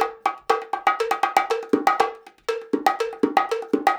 Index of /90_sSampleCDs/USB Soundscan vol.36 - Percussion Loops [AKAI] 1CD/Partition B/13-120BONGOS
120 BONGOS6.wav